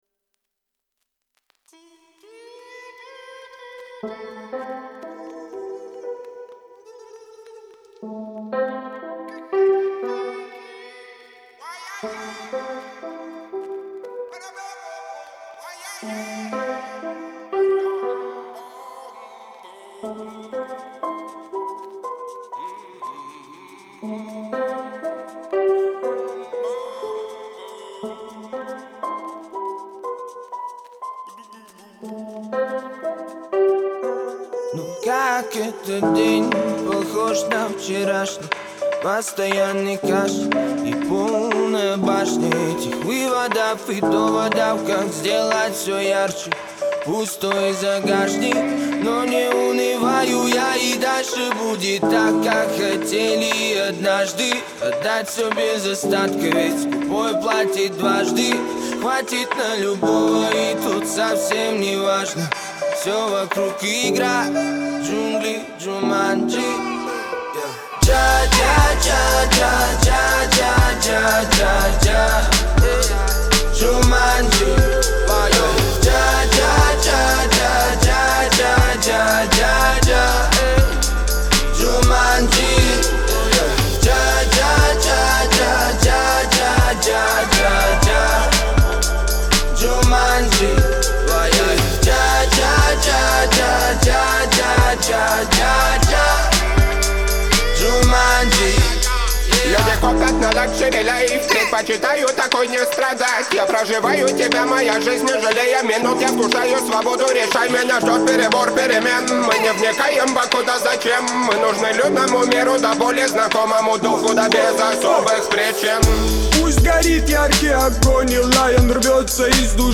Русский рэп